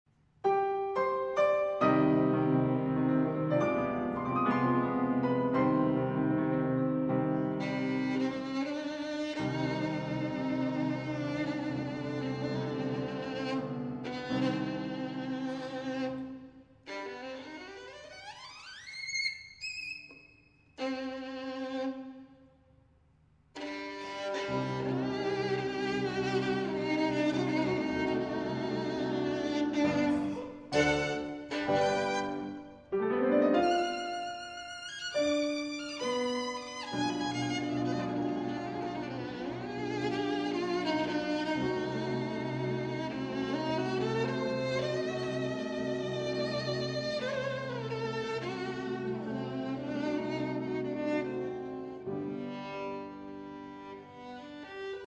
These samples were all recorded using Borman Instruments.
Violin
Live performance on her Borman of Sarasate - Zigeunerweisen for the Canadian Broadcasting Company.